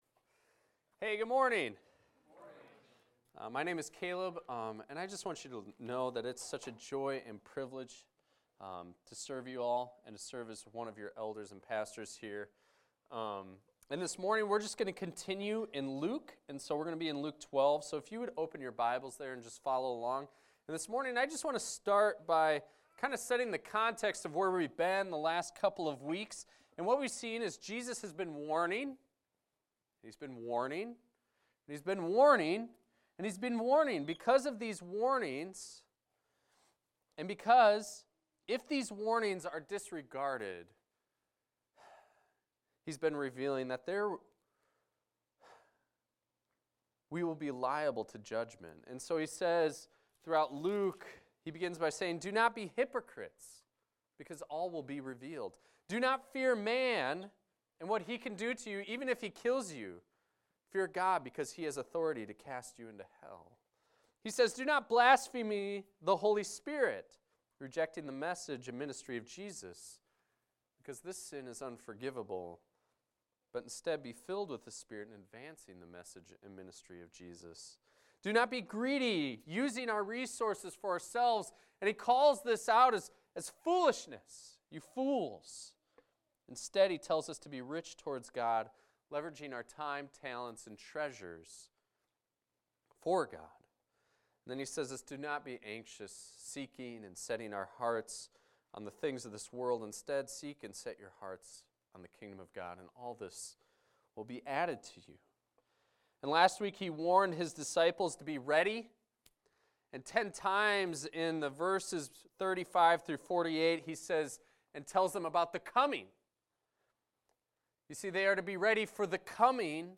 This is a recording of a sermon titled, "Luke 12:49-59."